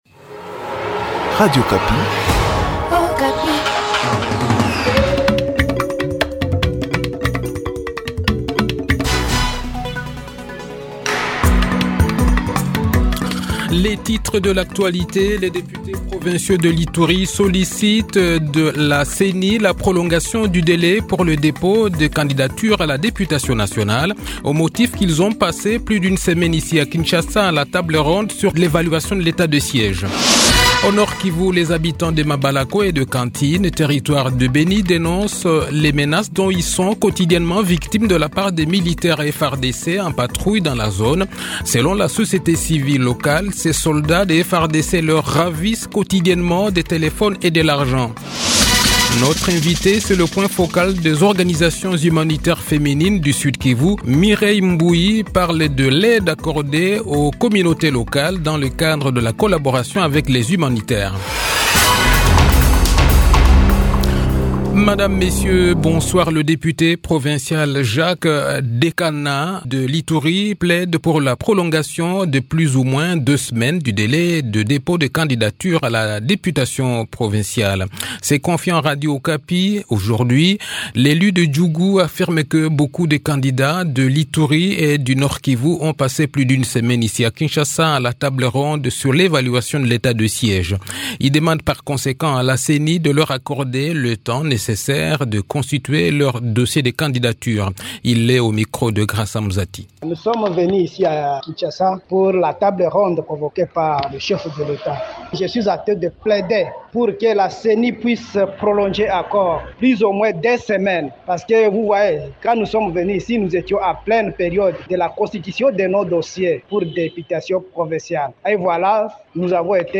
Le journal de 18 h, 20 Aout 2023